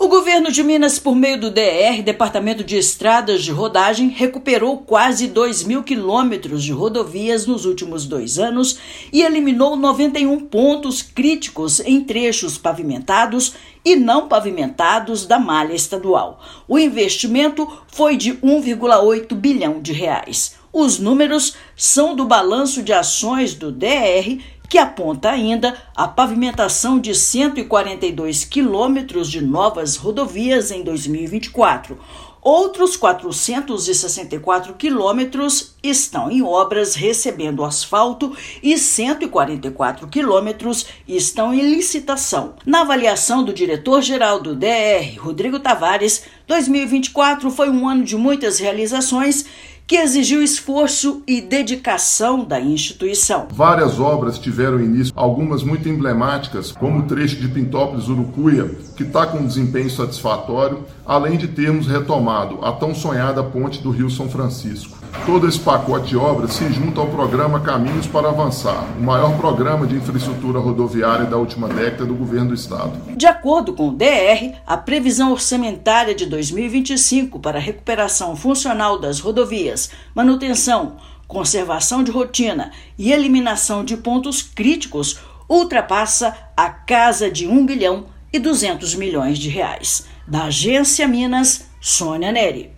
Autarquia fechou 2024 com quase R$ 2 bilhões em editais publicados e obras licitadas para 2025. Ouça matéria de rádio.